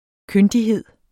Udtale [ ˈkøndiˌheðˀ ]